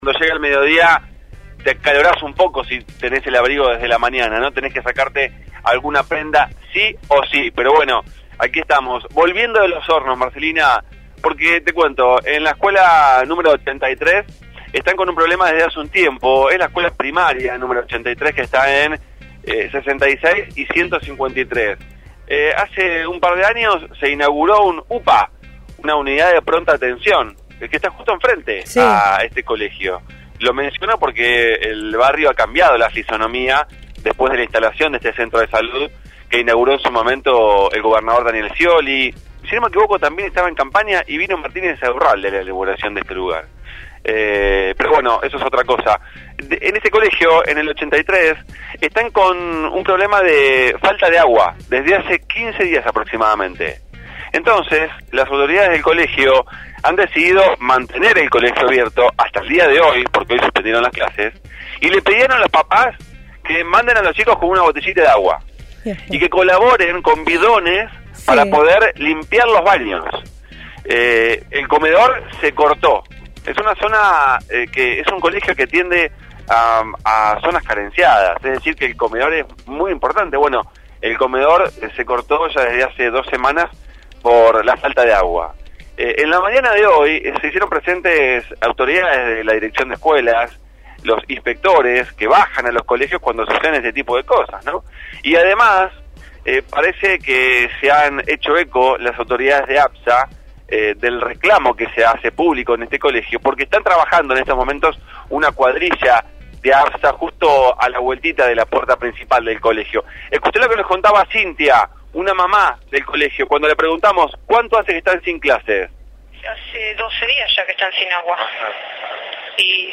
MÓVIL/ Falta de agua una escuela de Los Hornos